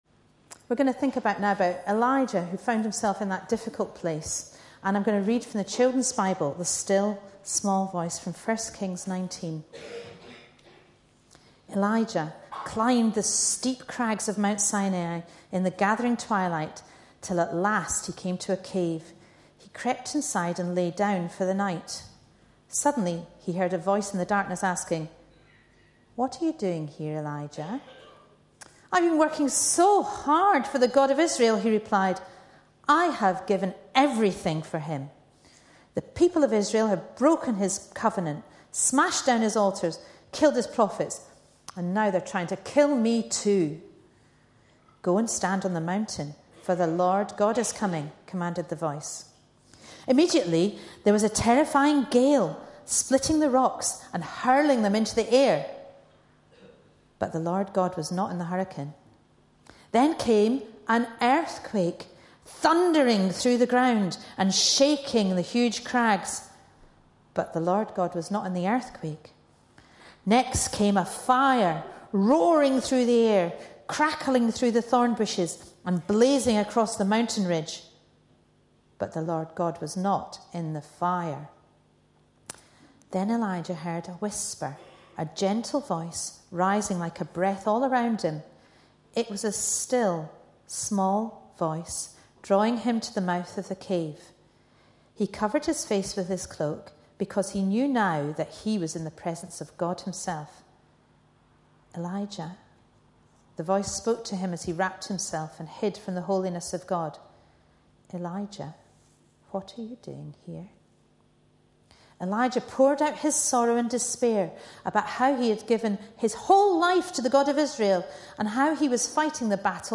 A sermon preached on 15th January, 2012.
A sermon preached on 15th January, 2012. 1 Kings 19:9-18 Listen online Details Reading is based on 1 Kings 19, with a reference to Psalms 139:2-4. This was a family service continuing a series of talks on Elijah, and earlier featured clips from 'The Lion King' film, and an animation of the Elijah story.